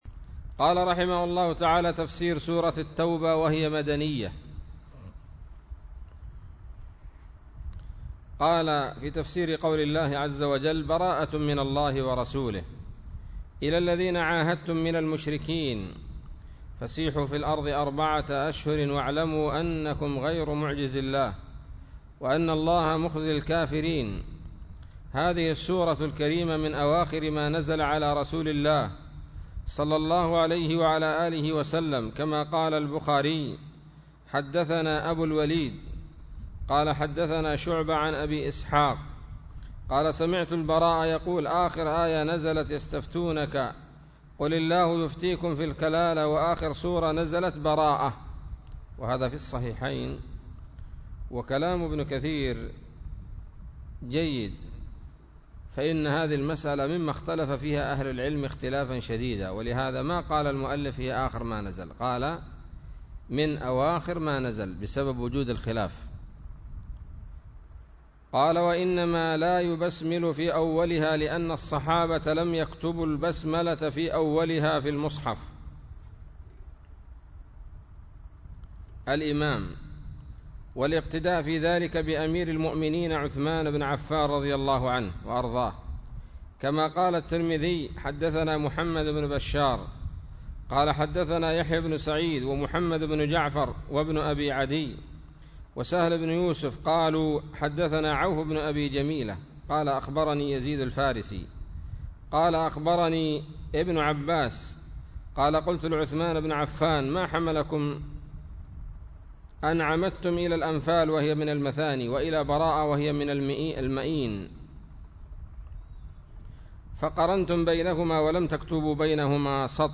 الدرس الأول من سورة التوبة من تفسير ابن كثير رحمه الله تعالى